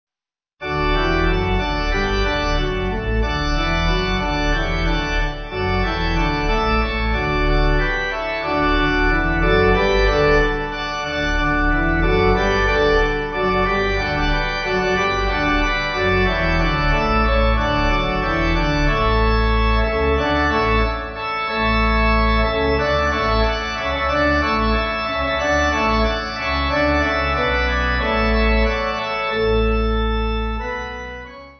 Organ
Easy Listening   D